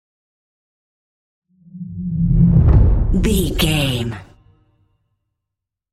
Cinematic whoosh to hit deep fast
Sound Effects
Fast
dark
intense
tension
woosh to hit